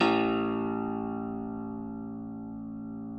53r-pno01-A-1.aif